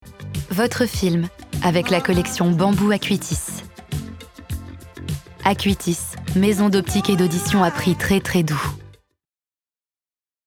Pub TV - Billboard - Acuitis
Je suis appréciée dans la Pub, les films institutionnels, le jeu vidéo, la narration et ma voix est parfois douce, parfois dynamique et chaleureuse.
5 - 40 ans - Mezzo-soprano